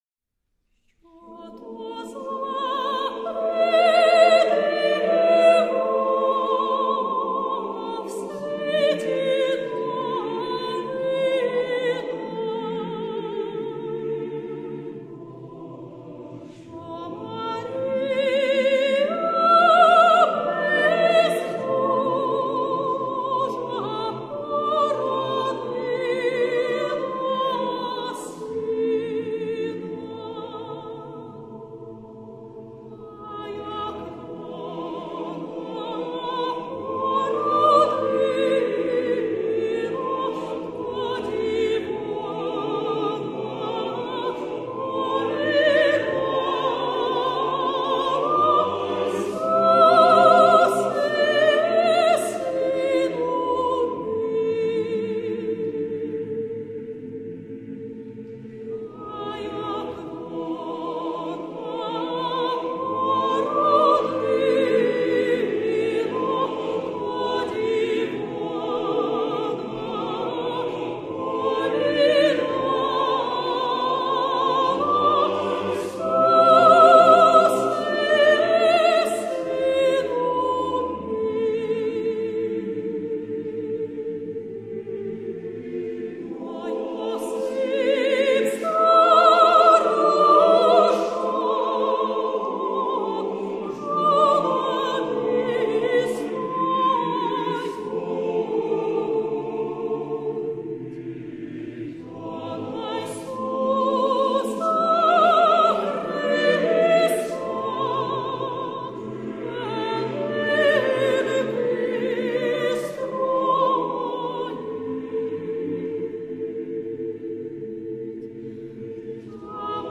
сопрано